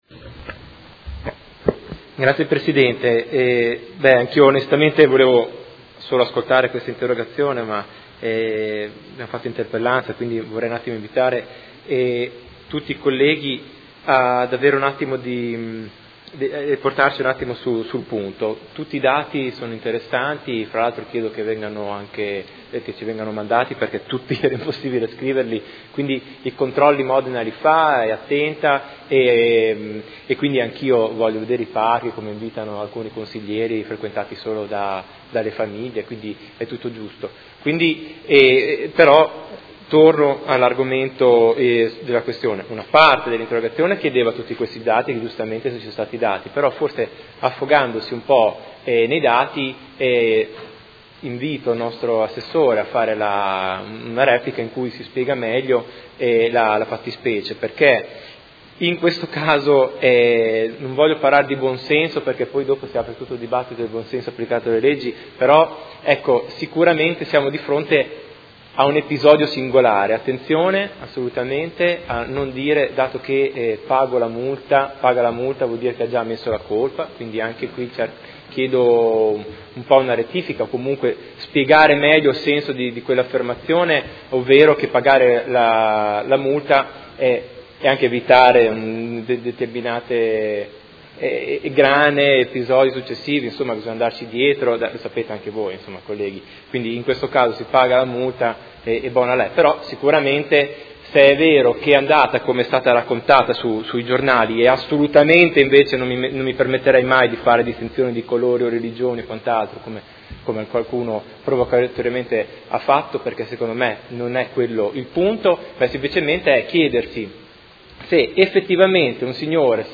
Marco Chincarini — Sito Audio Consiglio Comunale
Seduta del 26/06/2017. Dibattito su interrogazione del Consigliere Pellacani (FI) avente per oggetto: Quante contravvenzioni sono state elevate ai Giardini Ducali e negli altri parchi di Modena per violazioni alle norme anti alcool e antidegrado?